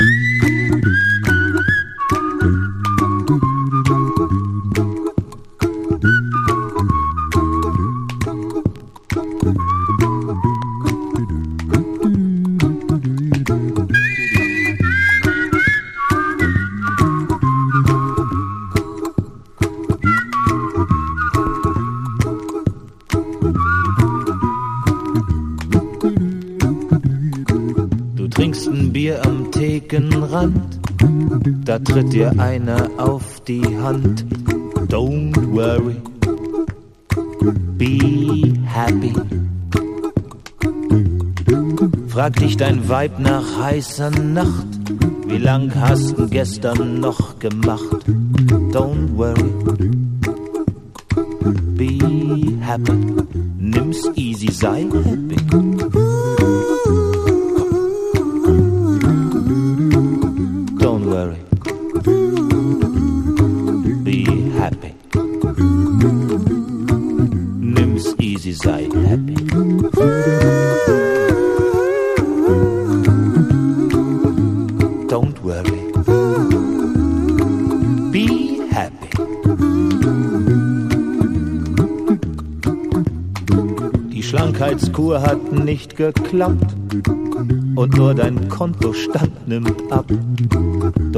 RECORDED AT – MAGIC SOUND STUDIO, MÜNCHEN